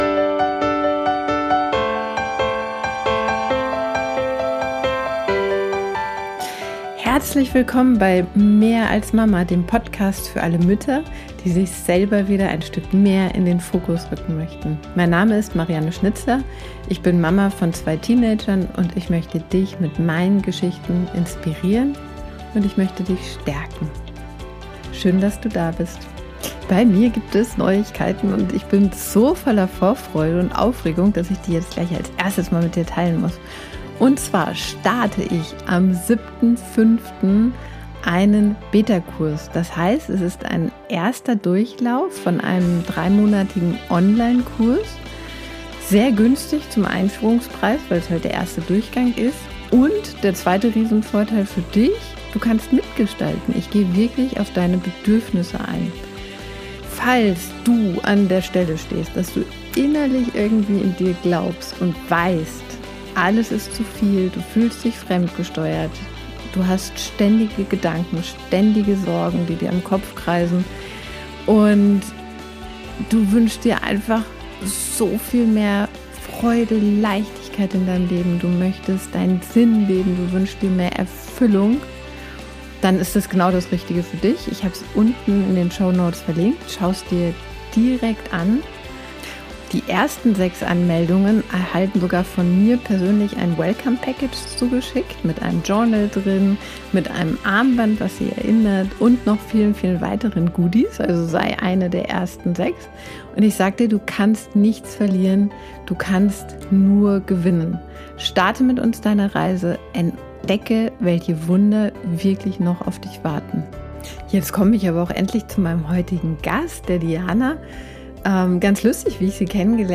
Ein Gespräch, das inspiriert, ermutigt und die Augen öffnet für die unendlichen Möglichkeiten, die in uns selbst liegen.